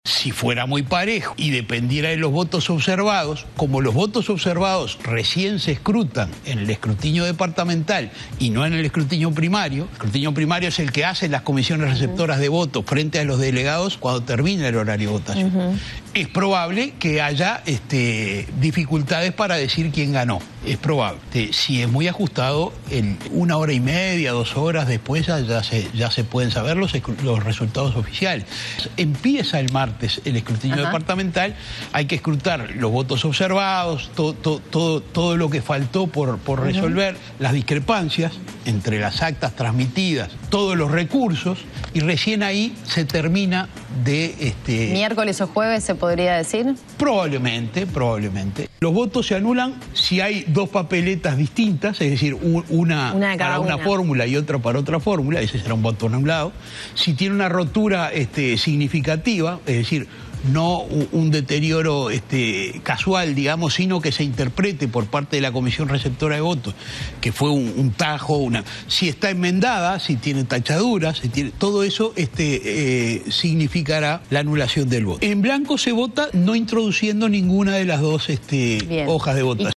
El ministro de la Corte Electoral, José Korzeniak expresó a Canal 10 que los resultados podrían demorarse si «es muy ajustada» la diferencia entre ambas fórmulas.  Dijo que «una hora y media o dos horas después del cierre de votación se va a tener el escrutinio primario».